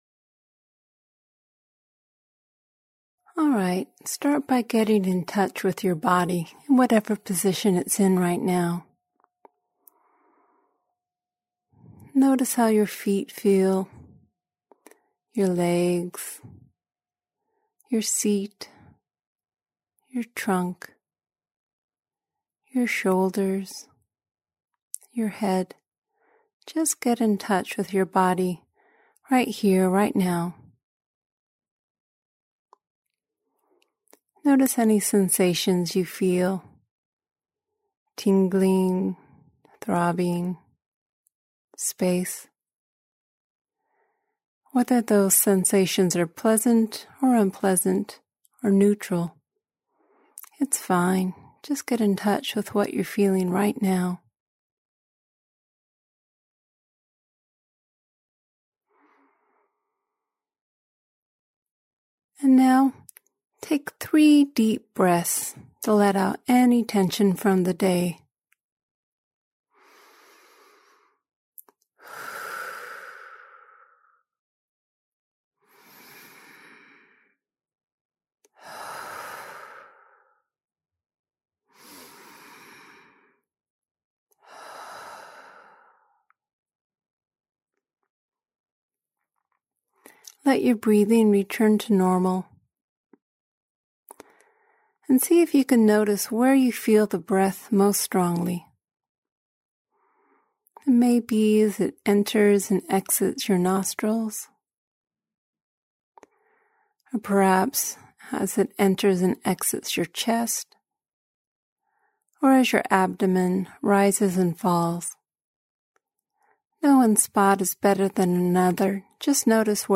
This classic breath meditation is infused with warmth and goodwill.